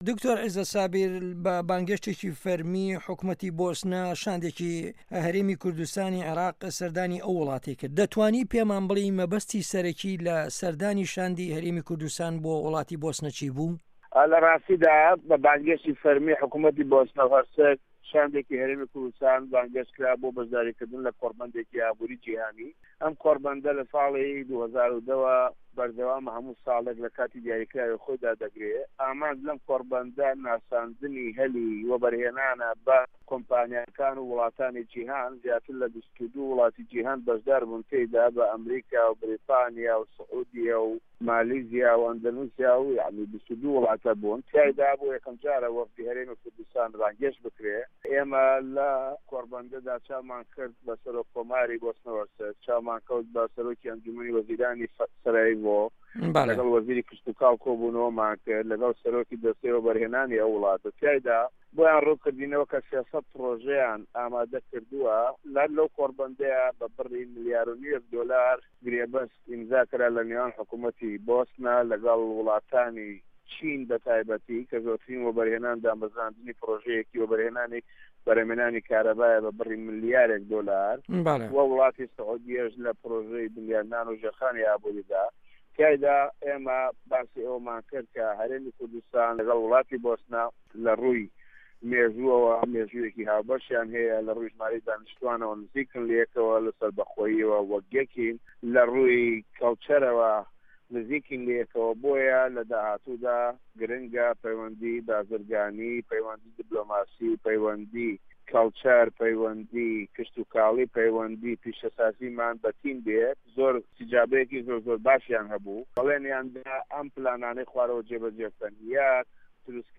وتوێژ لەگەڵ دکتۆر عیزەت سابیر